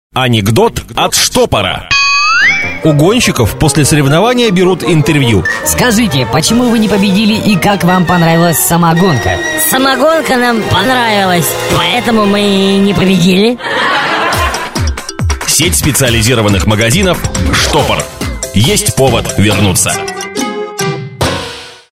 ролик для сети специализированных алкогольных магазинов "Штопор"
При наличии заинтересованности, вышлю Вам все 70 озвученных анекдотов.